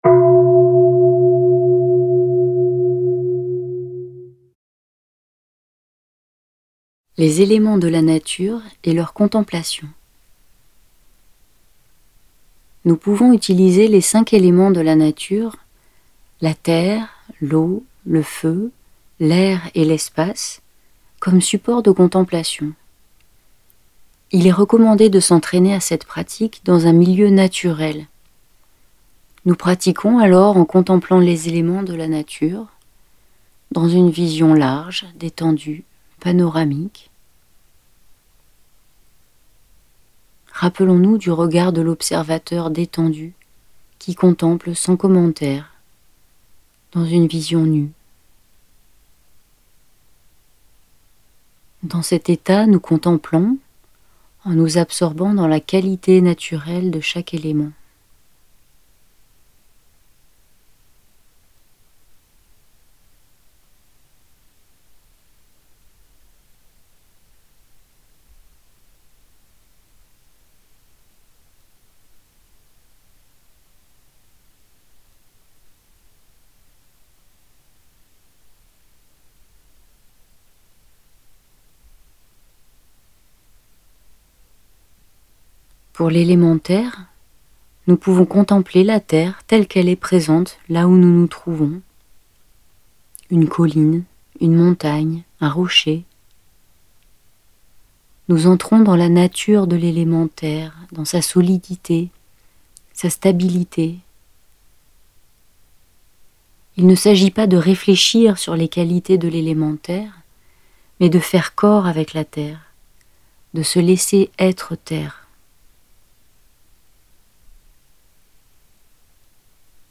Audio femme